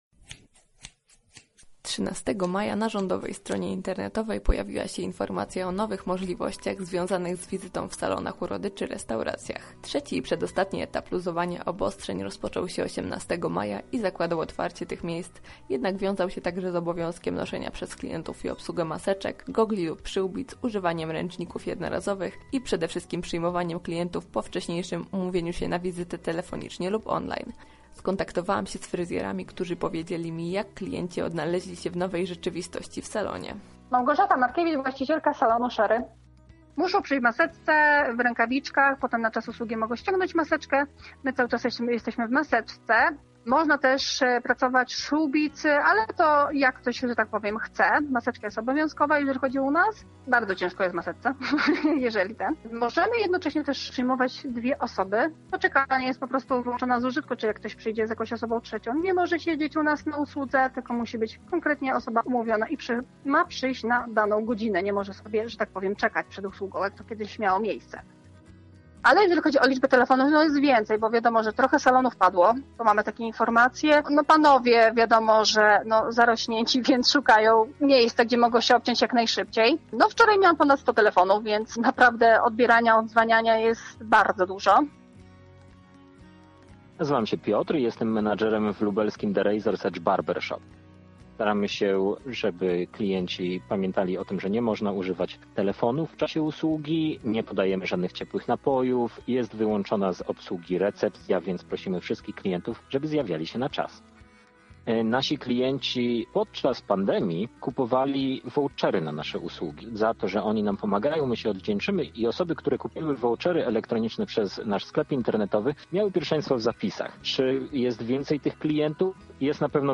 O pracy w obecnej sytuacji opowiedzieli lubelscy fryzjerzy.